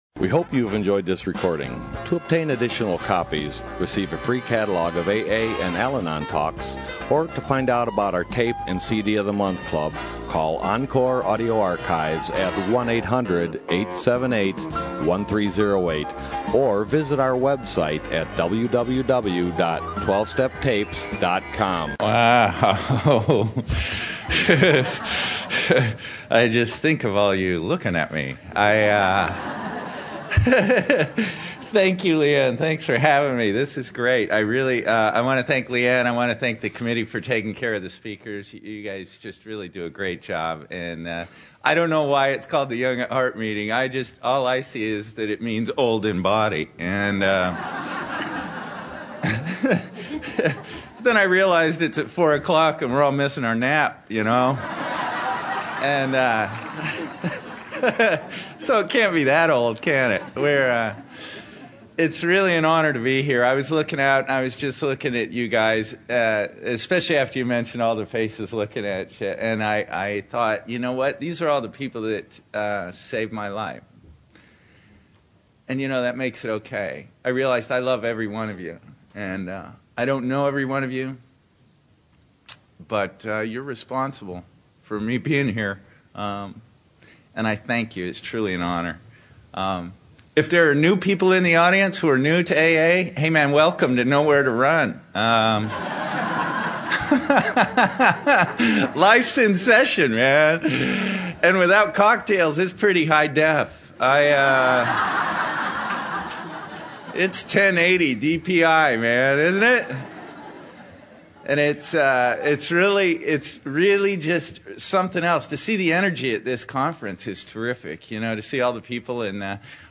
San Diego Spring Roundup 2009